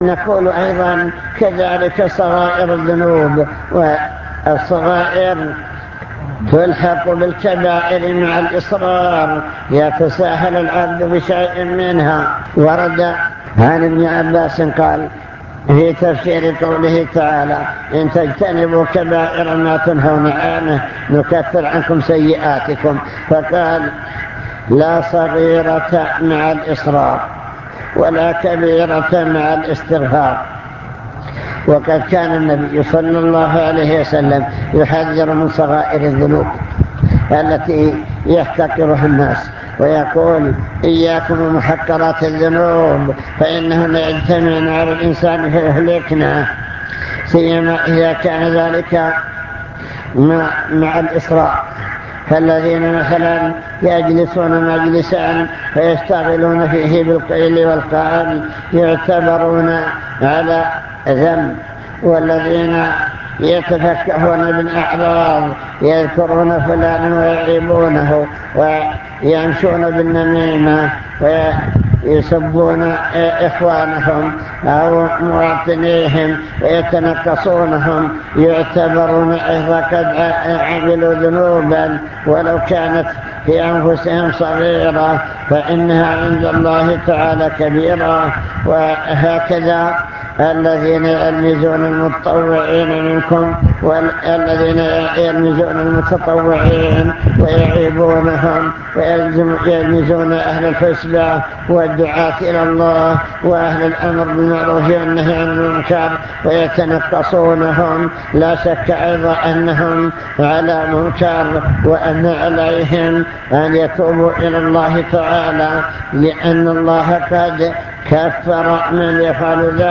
المكتبة الصوتية  تسجيلات - محاضرات ودروس  محاضرة بعنوان من يرد الله به خيرا يفقهه في الدين التحذير من بعض المنكرات